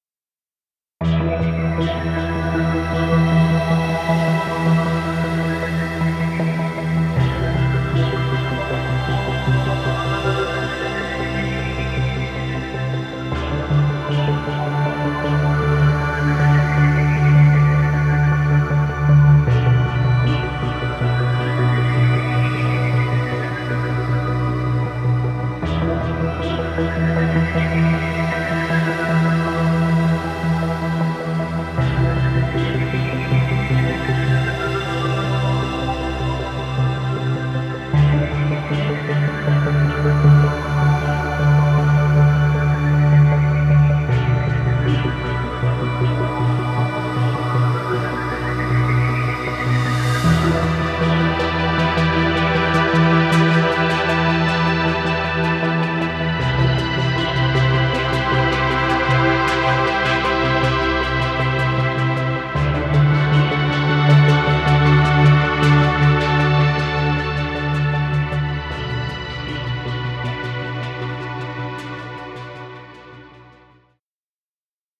Ambient music.